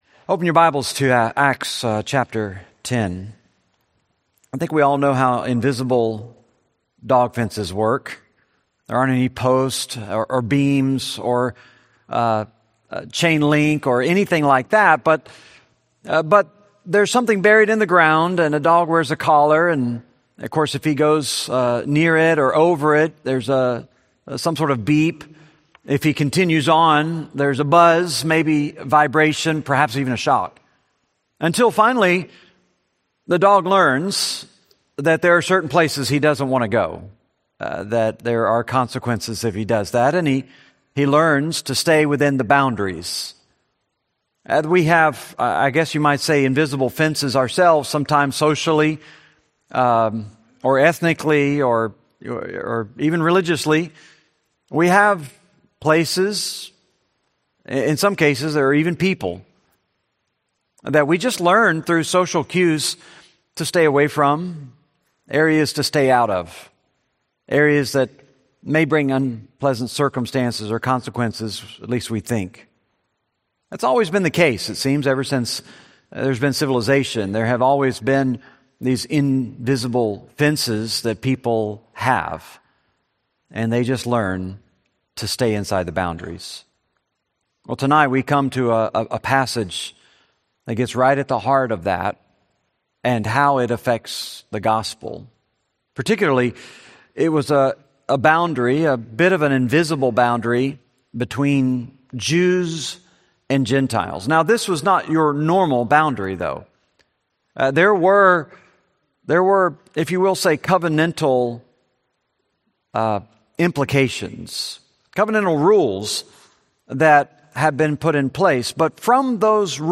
Series: Benediction Evening Service, Bible Studies